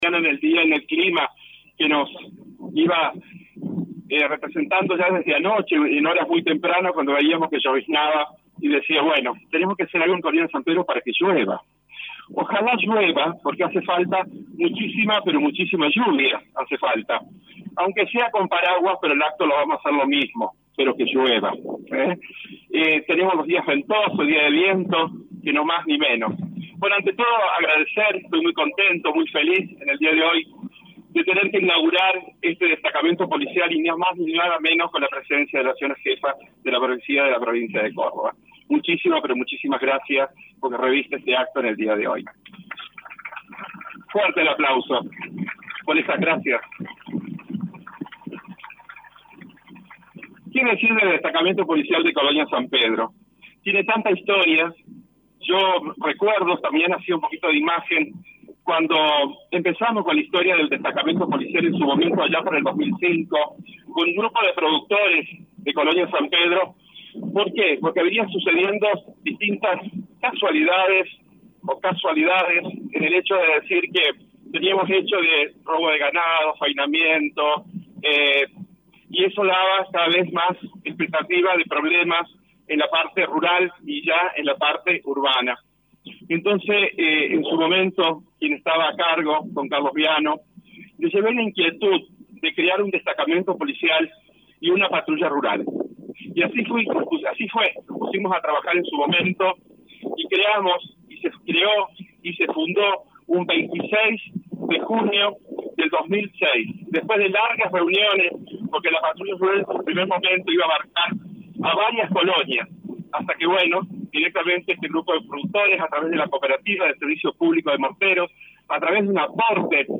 En el mediodía del martes, las autoridades de la Policía de la Provincia de Córdoba llegaron a Colonia San Pedro para dejar inaugurado el nuevo destacamento policial.
PALABRAS DE VICTOR BLENGINO
En su mensaje, el presidente Comunal de Colonia San Pedro Victor Blengino manifestó su alegría al inaugurar este destacamento policial y afirmó lo necesario que era contar con un edificio propio ya que el anterior era prestado.